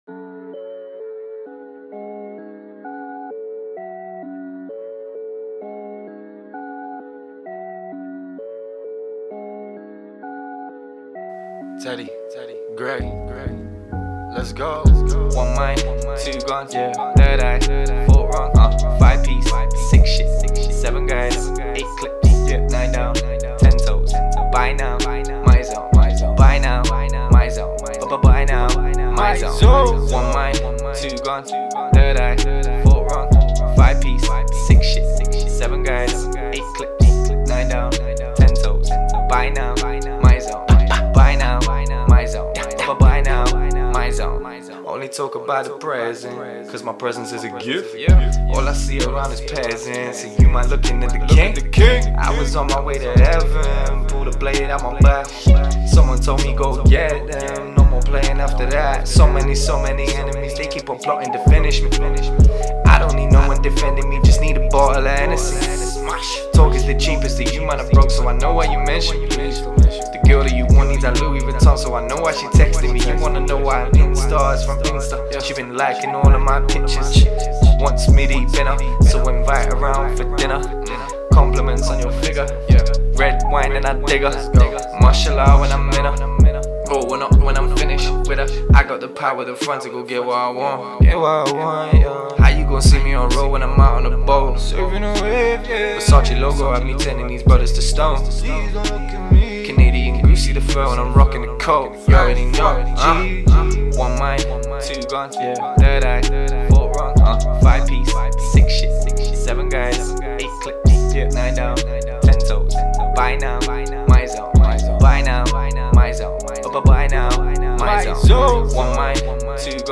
you were having fun with the reverb and paning left/right